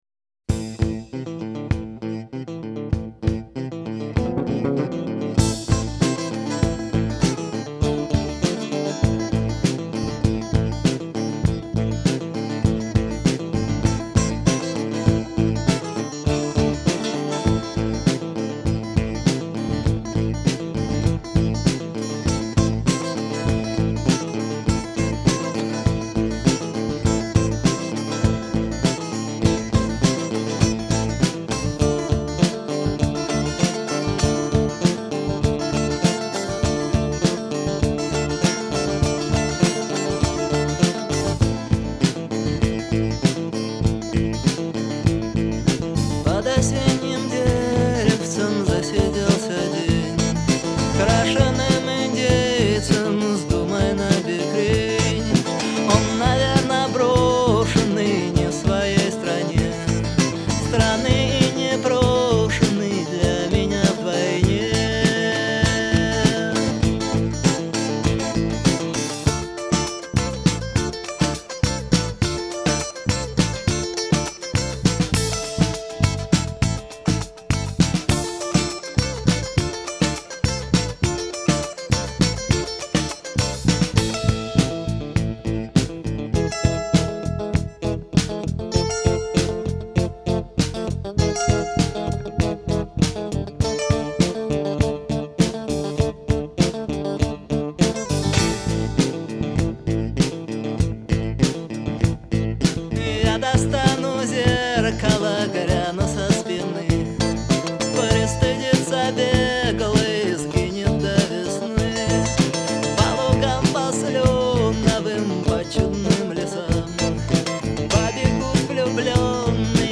Поп рок